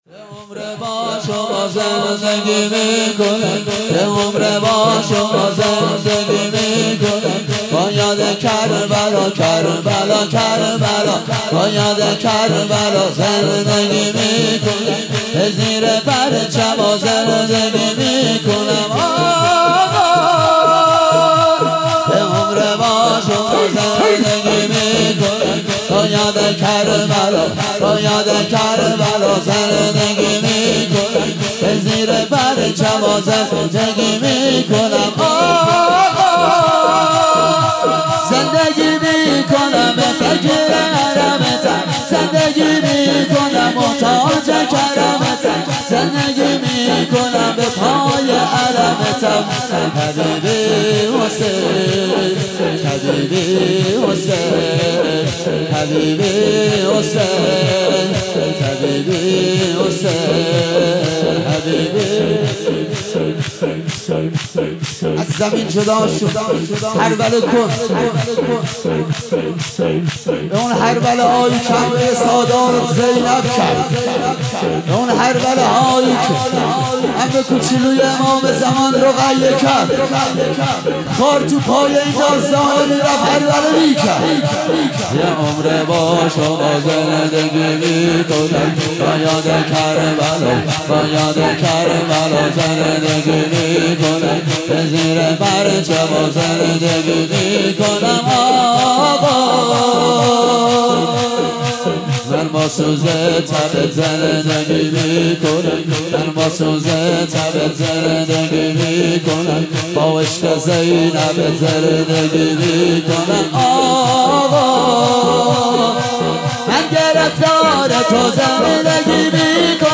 فاطمیه
شور مداحی